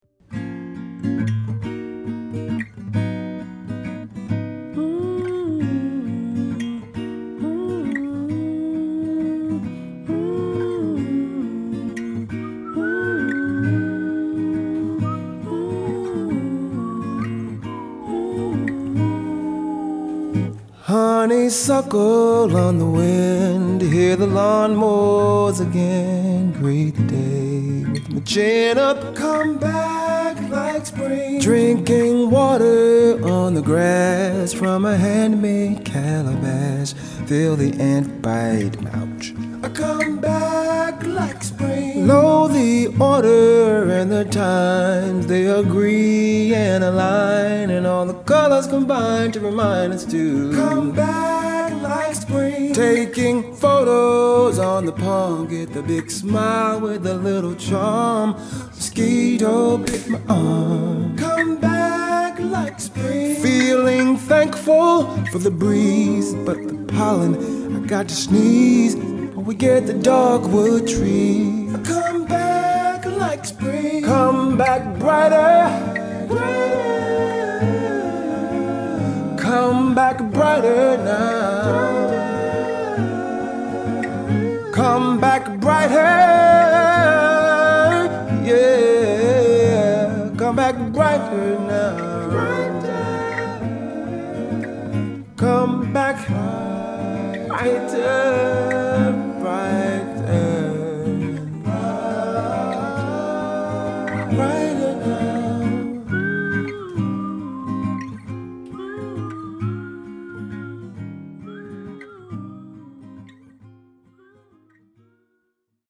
this song is comes off hymn like